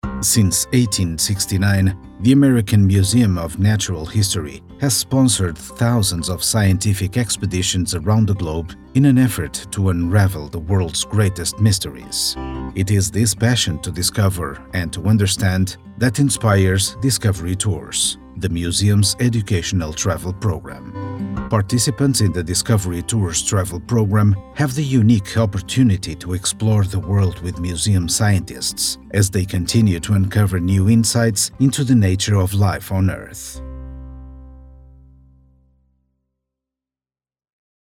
Native speaker Male 30-50 lat
Possesses a mature, baritone voice.
Demo - język angielski